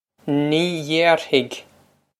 Ní dhéarfaidh Nee yayr-hig
Pronunciation for how to say
This is an approximate phonetic pronunciation of the phrase.